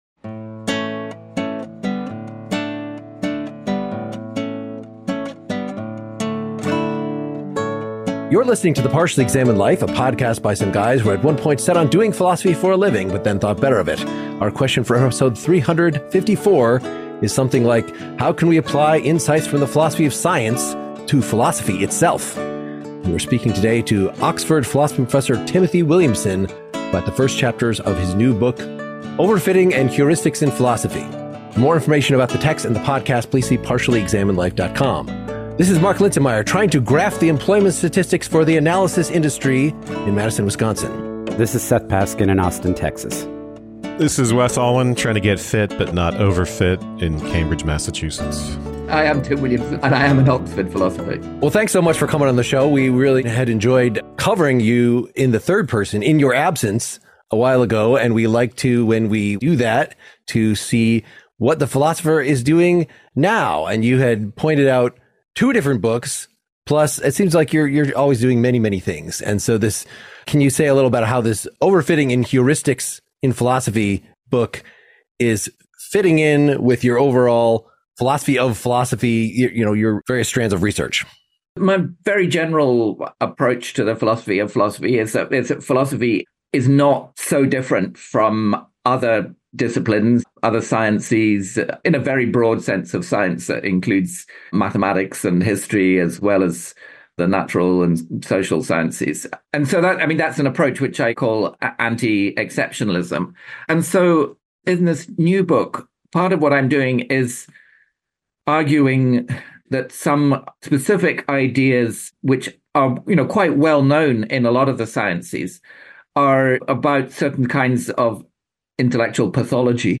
Oxford philosophy professor Timothy Williamson talks to us about his new book, Overfitting and Heuristics in Philosophy.